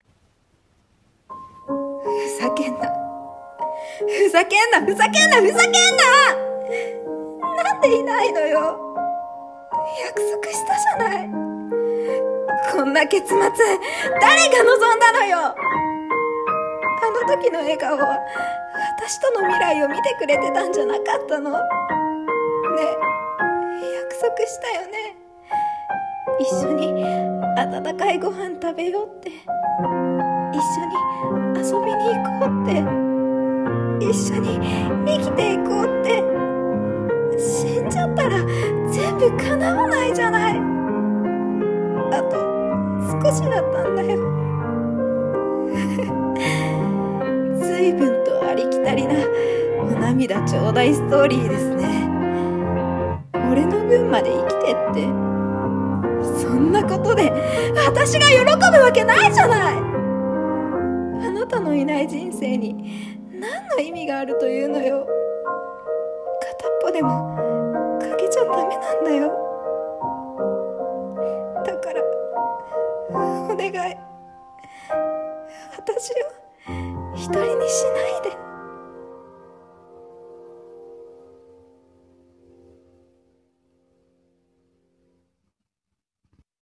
声劇台本】果たせぬ約束